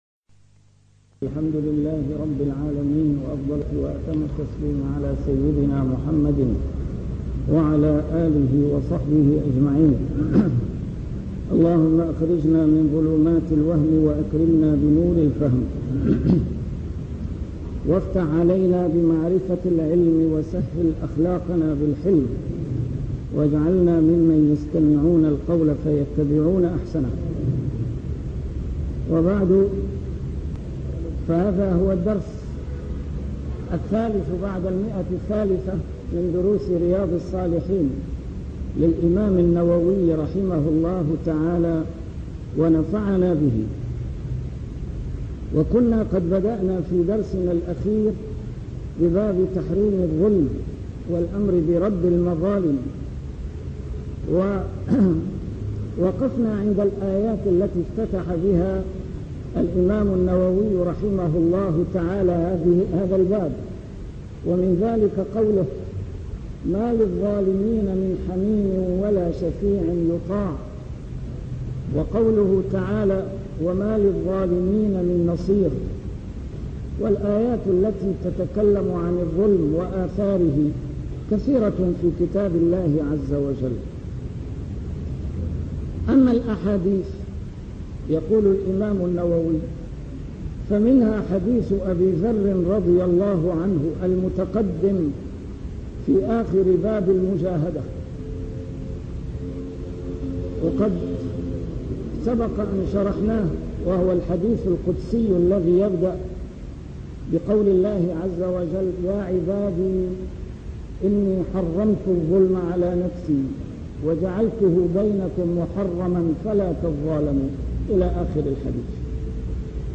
A MARTYR SCHOLAR: IMAM MUHAMMAD SAEED RAMADAN AL-BOUTI - الدروس العلمية - شرح كتاب رياض الصالحين - 303- شرح رياض الصالحين: تحريم الظلم